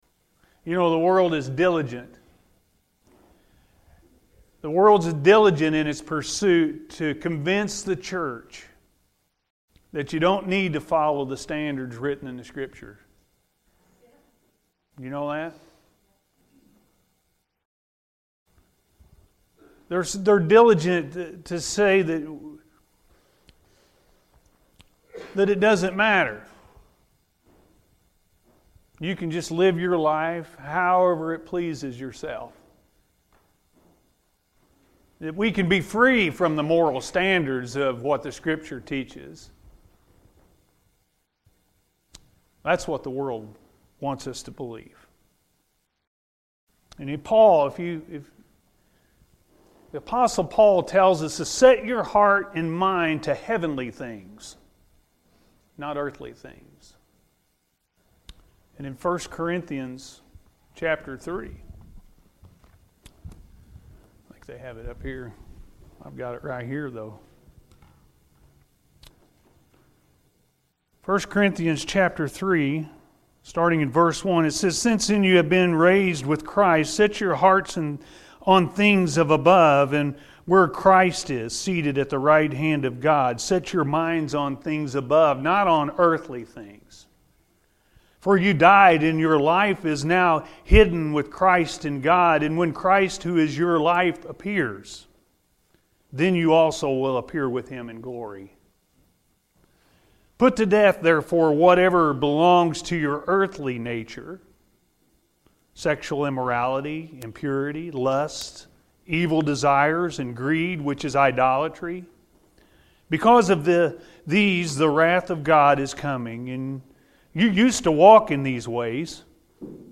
Set Your Mind On Heavenly Things-A.M. Service